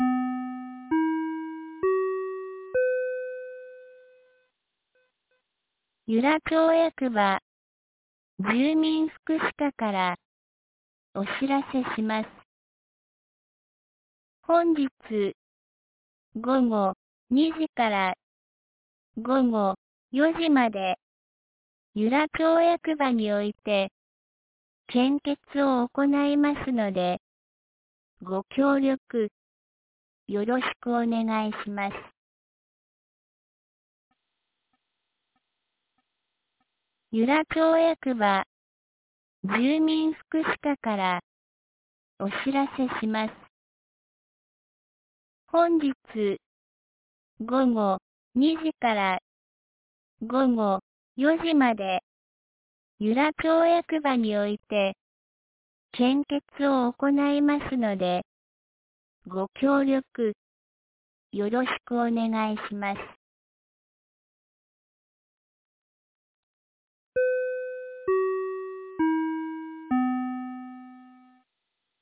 2025年08月19日 07時51分に、由良町から全地区へ放送がありました。